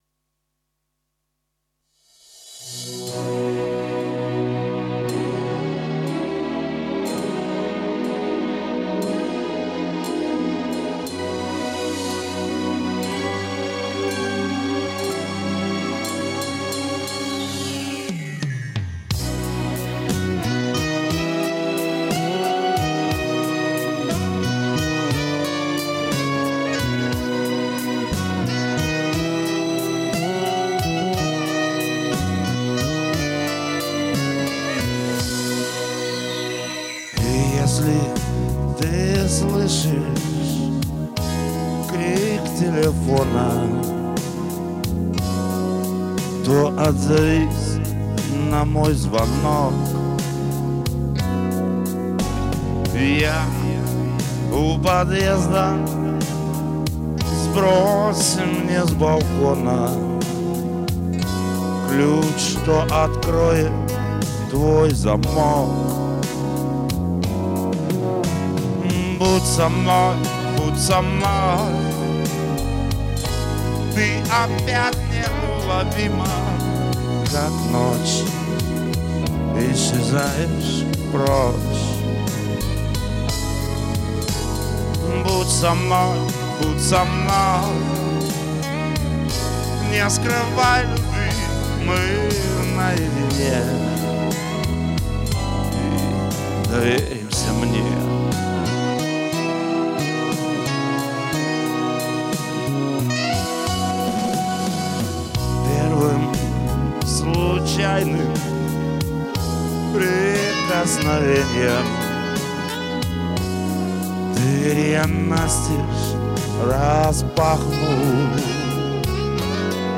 Очень точно интонирование и подача песни блюзовая